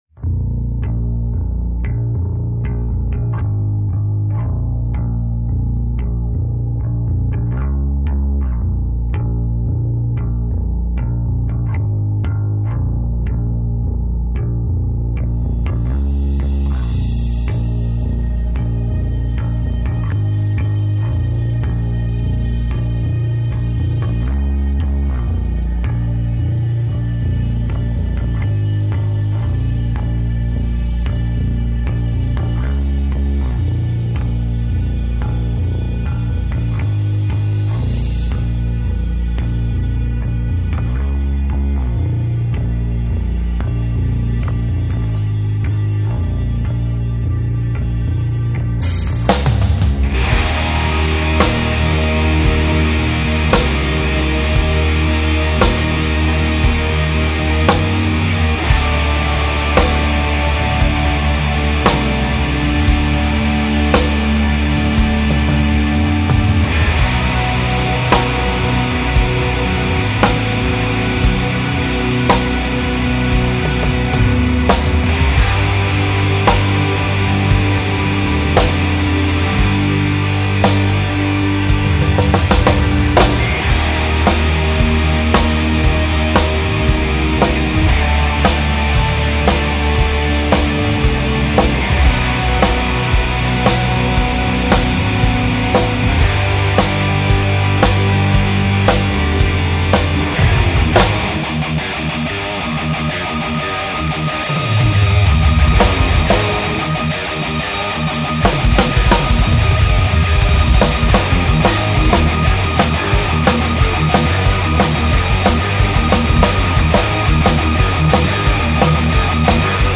Dlhé zimné večery spôsobili demo.
Zpevak vybornej!
Bubenik bohuzel nehraje moc dobre... zejmena v prechodech to dost kulha.